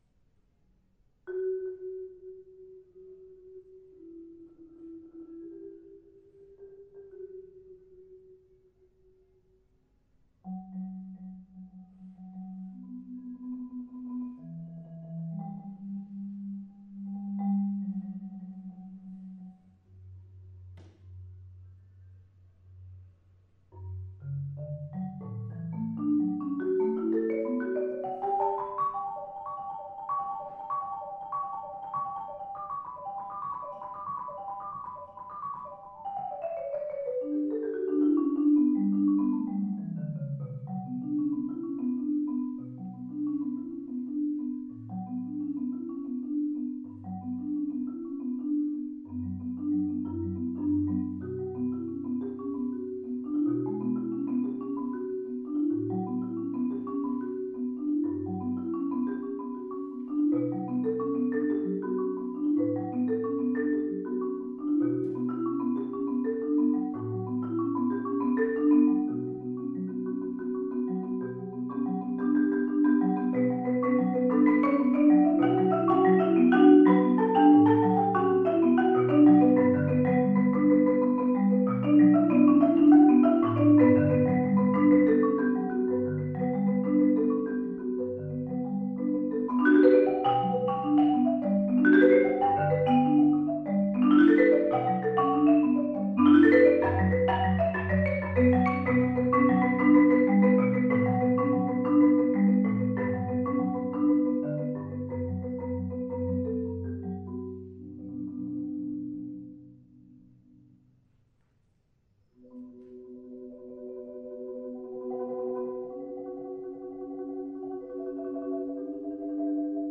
Genre: Marimba (4-mallet)